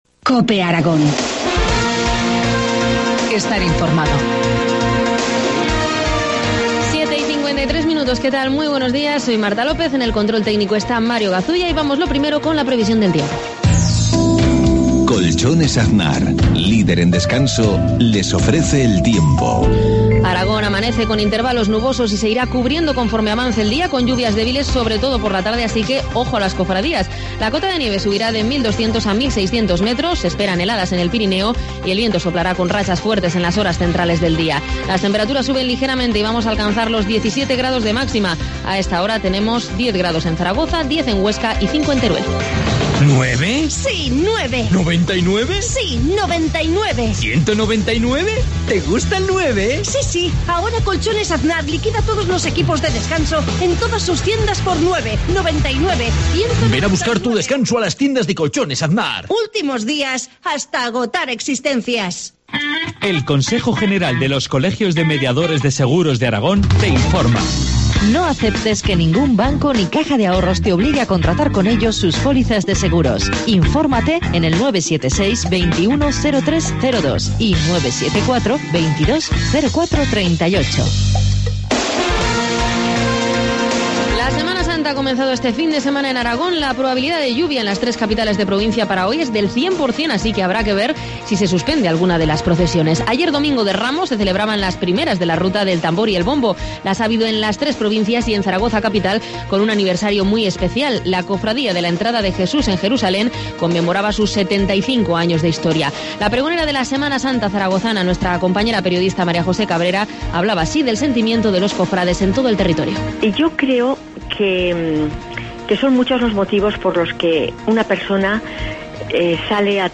Informativo matinal, lunes 25 de marzo, 7.53 horas